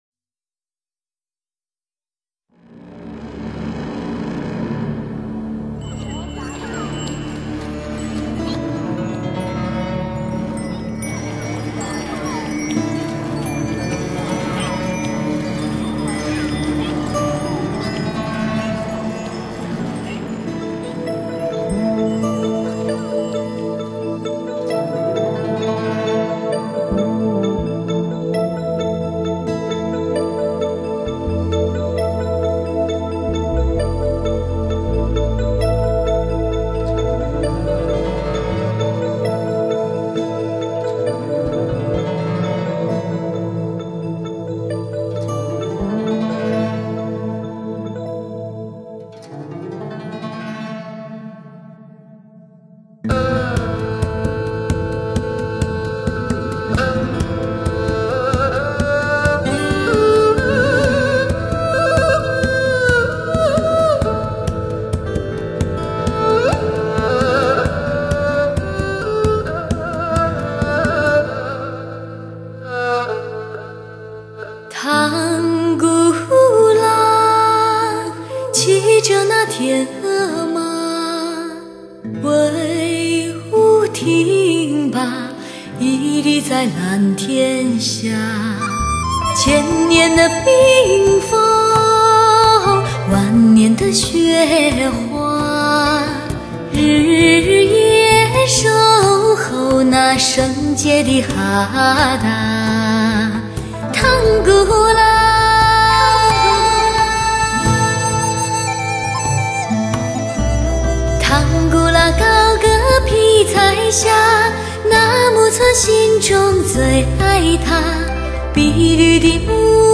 只有真正的绝对的HIFI人声，才能被称为天籁。